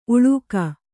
♪ uḷūka